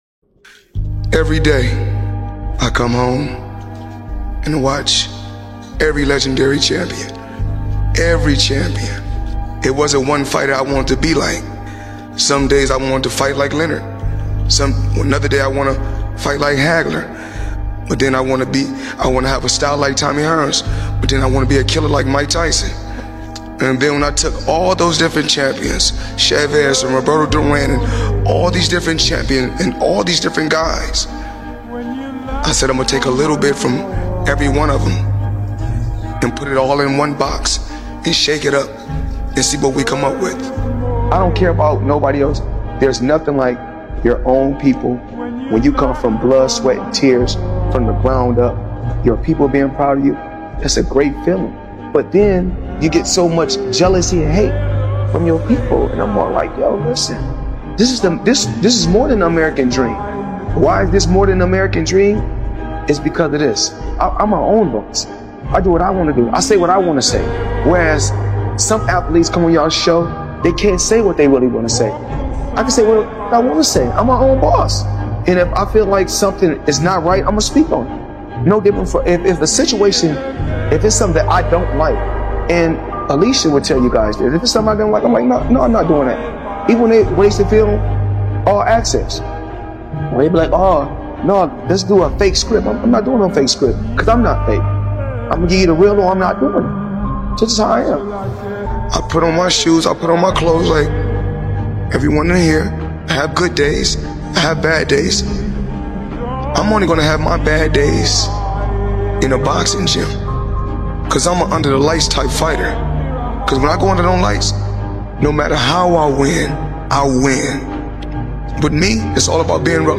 The Will To Win | Powerful Motivational Speech 2025